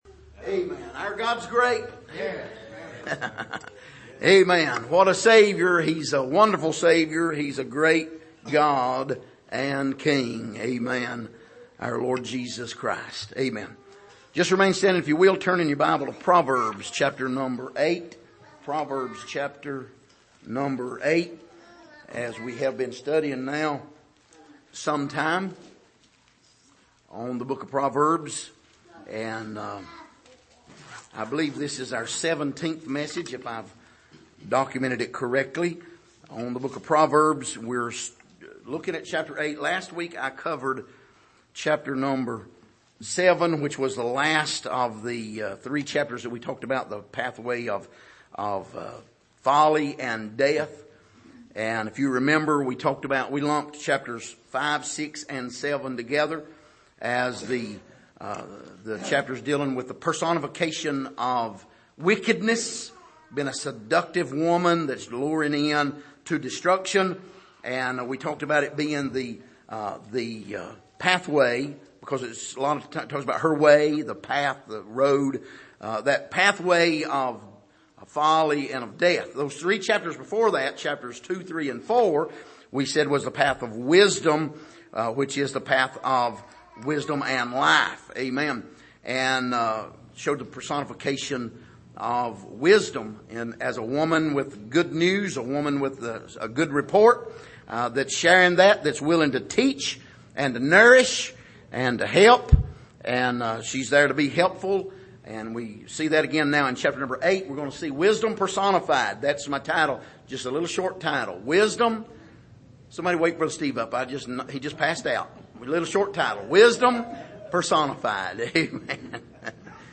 Proverbs The Book Of Wisdom Passage: Proverbs8:1,4,12,14 Service: Sunday Evening Wisdom Personified Part 1 « Are You In The Book?